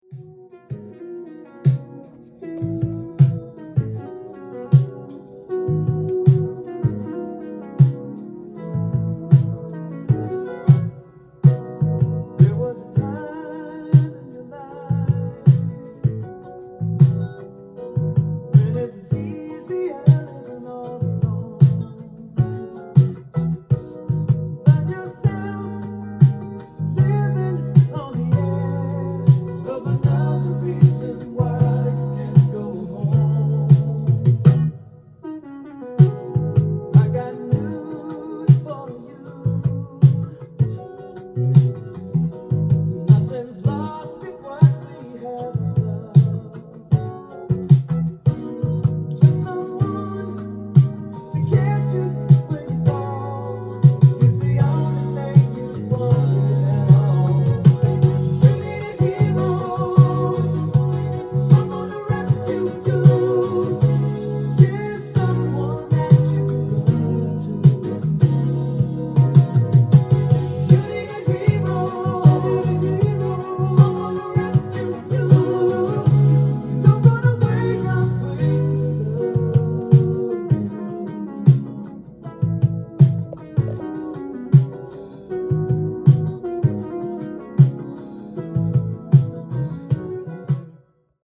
店頭で録音した音源の為、多少の外部音や音質の悪さはございますが、サンプルとしてご視聴ください。
清涼感のある歌声、洗練された無駄の無いアレンジで構成された内容最高のメロウな1枚です！
音が稀にチリ・プツ出る程度